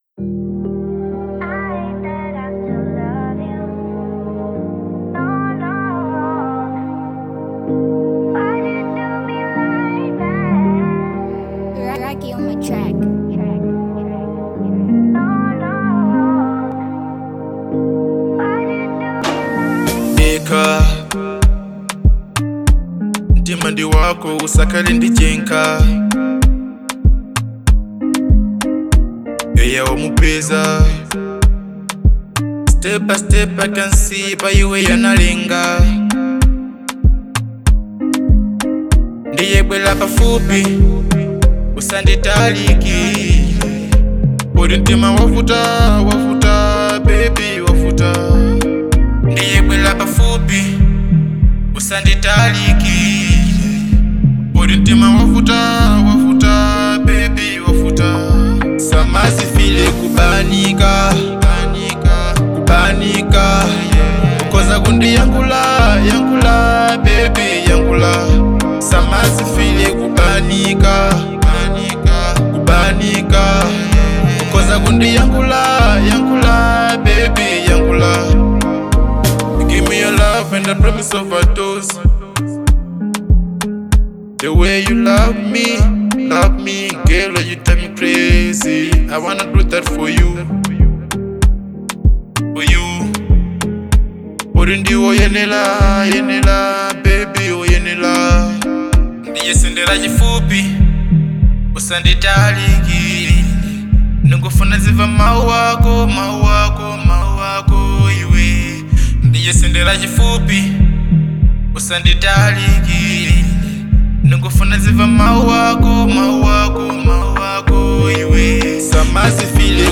Genre : Afro Beat